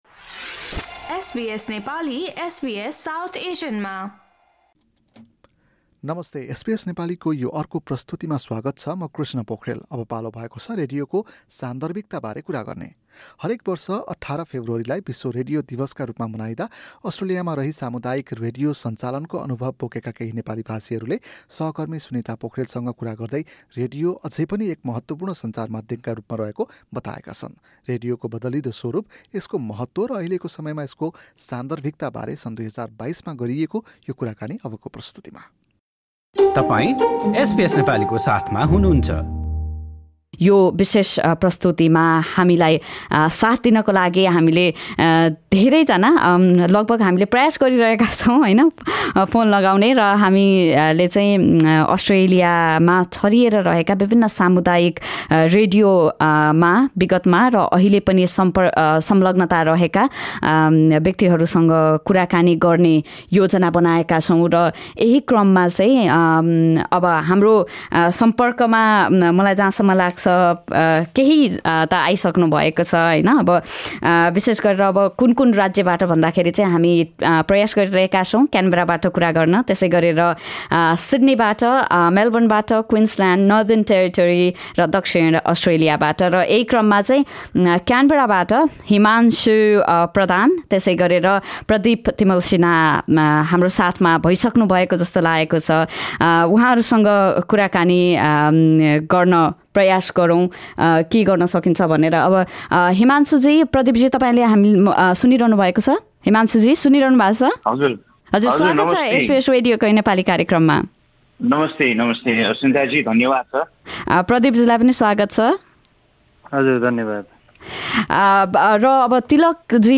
रेडियोको महत्त्व र अहिलेको समयमा यसको सान्दर्भिकताबारे गरेको कुराकानी सुन्नुहोस्।